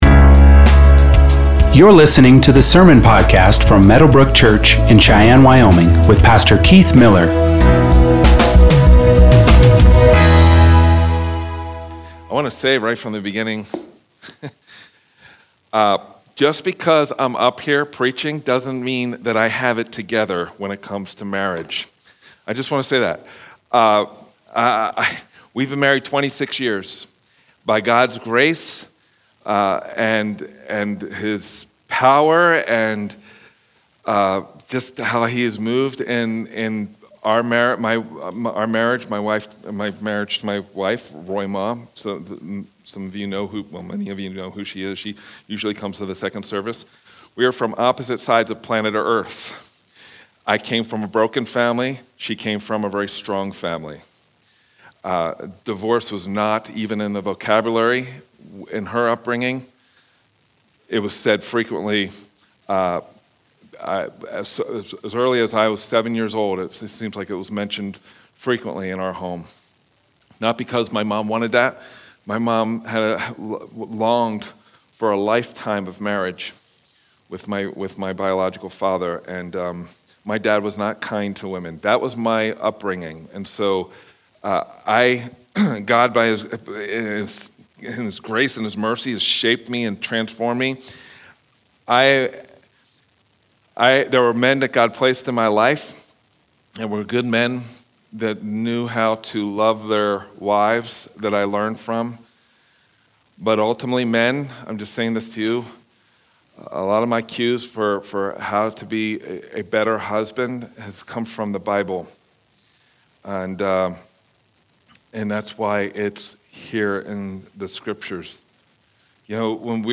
Sermons | Meadowbrooke Church